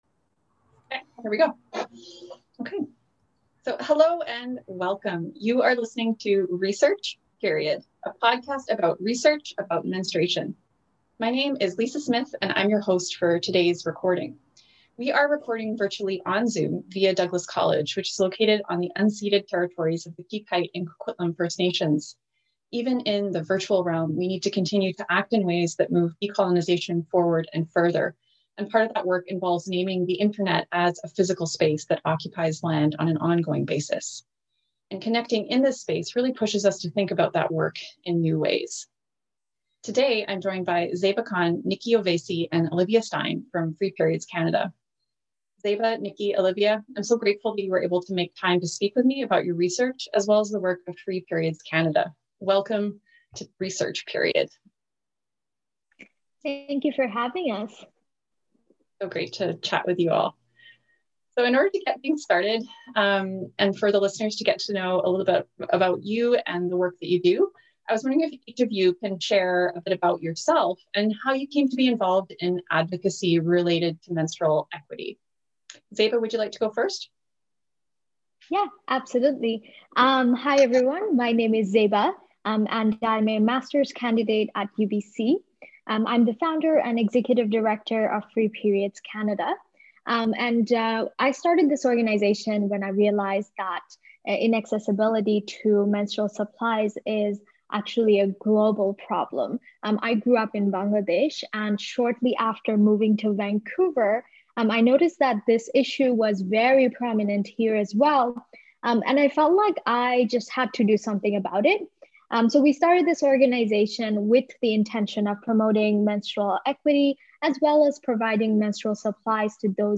Research. Period. (Episode 3): A conversation